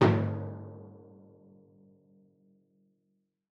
Timpani Large
Timpani7B_hit_v5_rr2_main.mp3